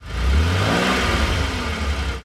CosmicRageSounds / ogg / general / cars / rev_out2.ogg
rev_out2.ogg